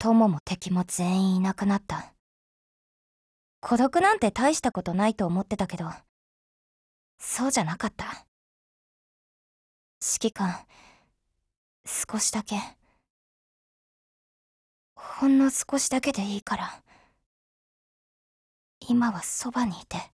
贡献 ） 协议：Copyright，其他分类： 分类:SCAR-H 、 分类:语音 您不可以覆盖此文件。
SCARH_DIALOGUEWEDDING_JP.wav